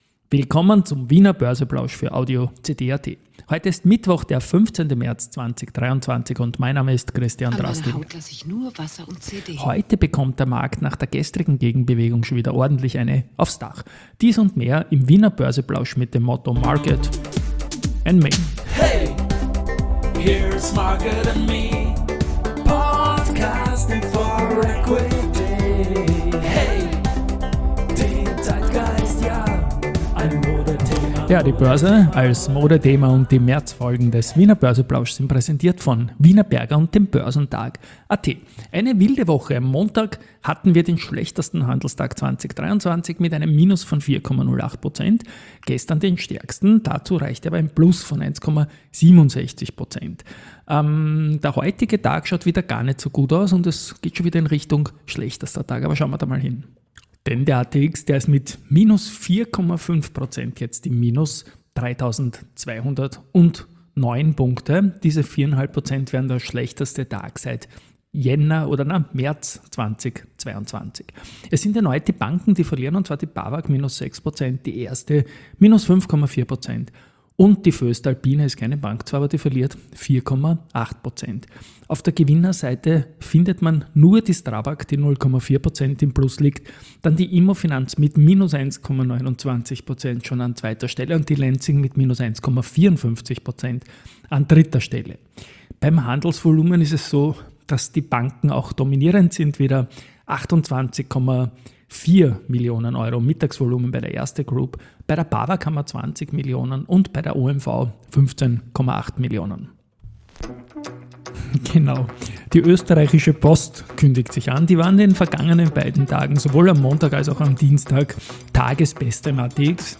In Folge S4/20 geht es erneut um eine ordentliche Korrektur mit -4,5 Prozent Zwischenbilanz für den Leitindex, das wäre der schlimmste Tag seit März 2022. Zahlen gibt es von der VIG und - Soundlogo-unterlegt - von der Post, News von Palfinger und AMAG, Research zu Mayr-Melnhof, Andritz, A1 Telekom Austria und ams Osram.